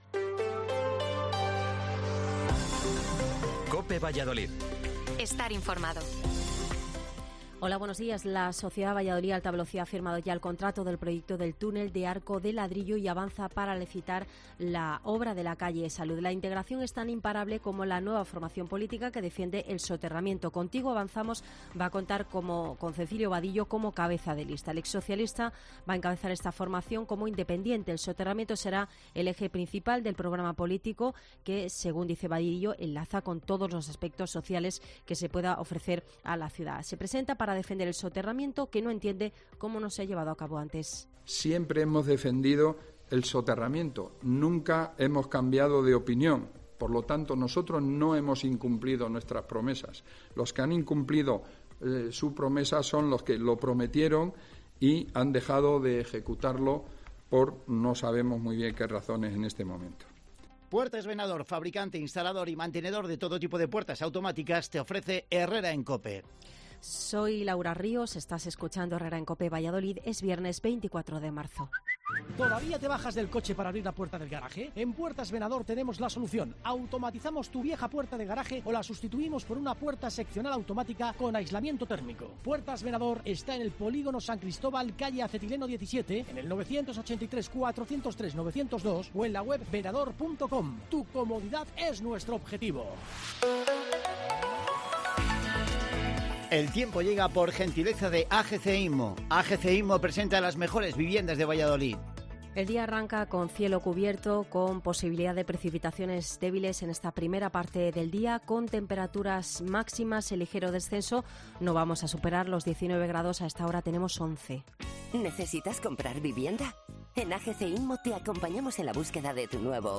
Informativo Matinal 8:24